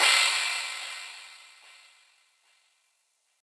Crash [8Bit].wav